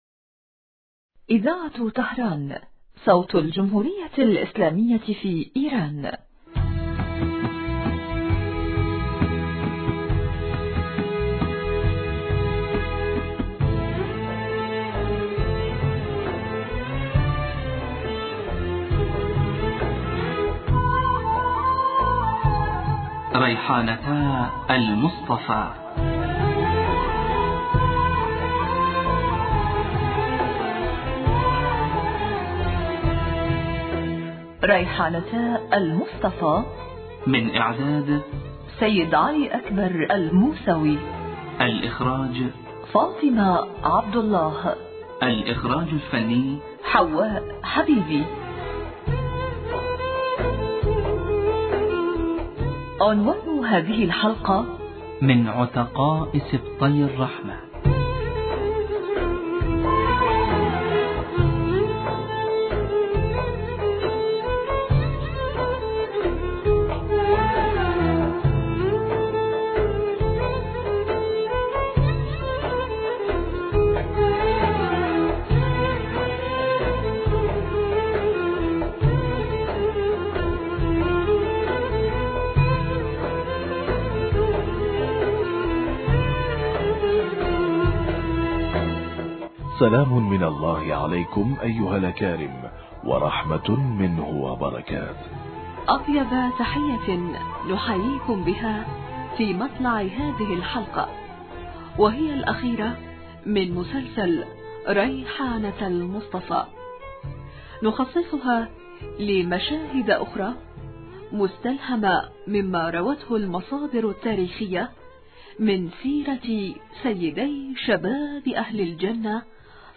مسلسل تاريخي